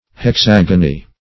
\Hex*ag"o*ny\